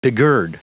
Prononciation du mot begird en anglais (fichier audio)
Prononciation du mot : begird